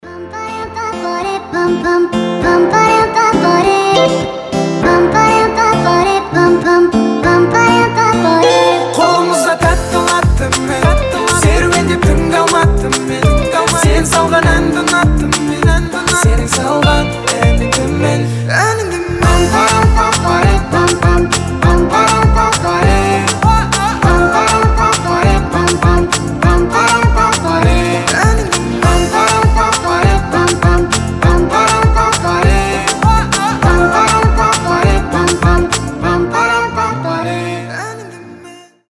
• Качество: 320, Stereo
гитара
мужской голос
забавные
детский голос
легкие